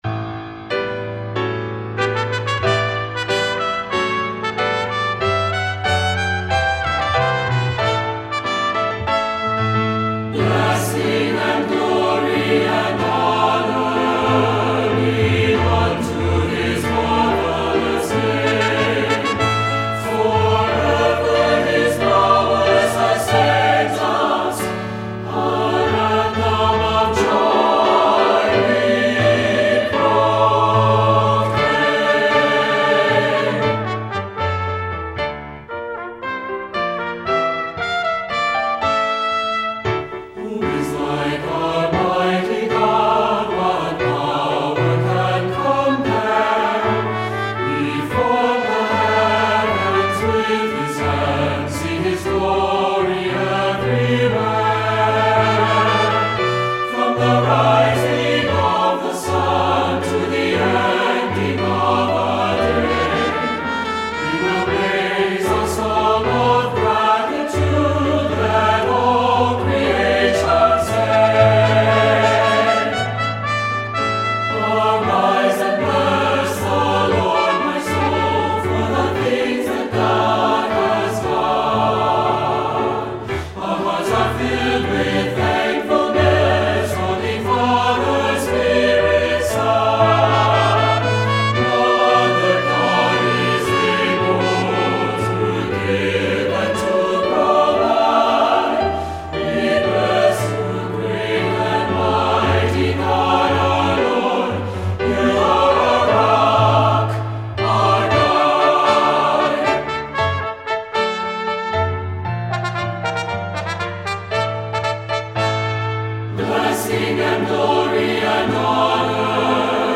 SAB Church Choir Music
Voicing: SATB